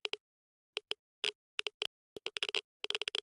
Minecraft Version Minecraft Version latest Latest Release | Latest Snapshot latest / assets / minecraft / sounds / ambient / nether / basalt_deltas / click7.ogg Compare With Compare With Latest Release | Latest Snapshot
click7.ogg